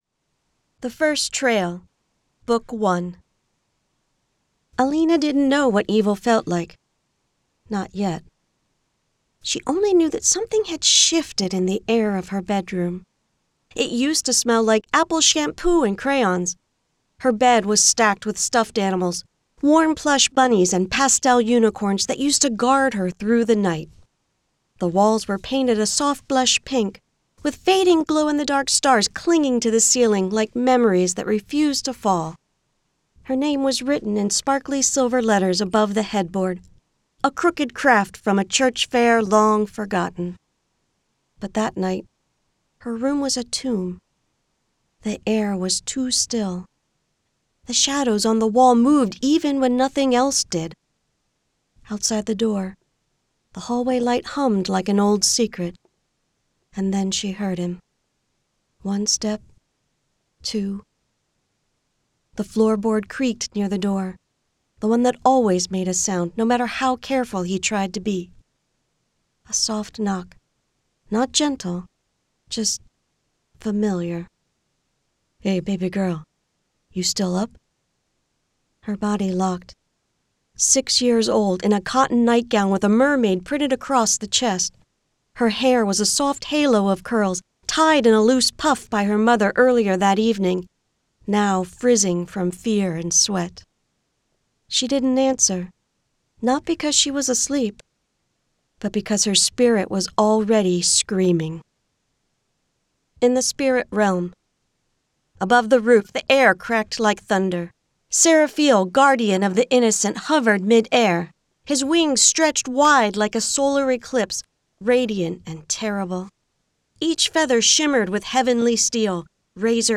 I have an every-man voice that reminds you of the guy next door. I also have a smooth texture to my voice that brings to mind Smucker's Jam.
0921The_First_Trail_-_Retail_Sample.mp3